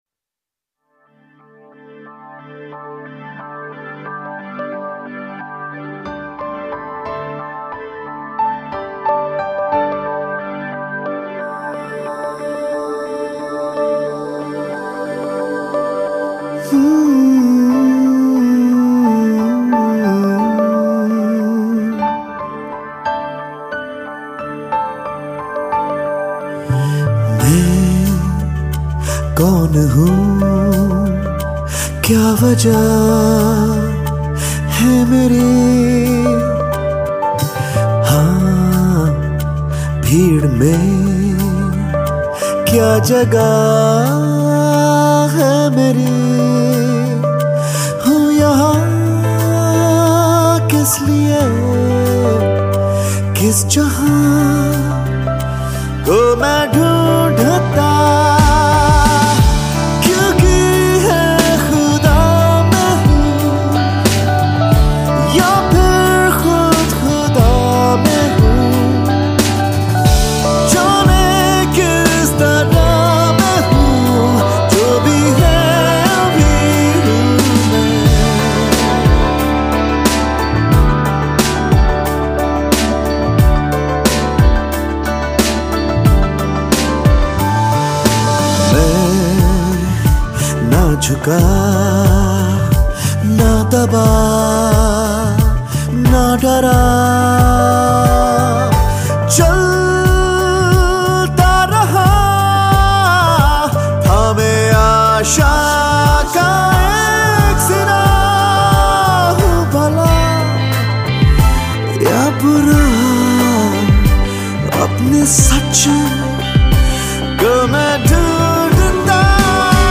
Pop Songs
Indian Pop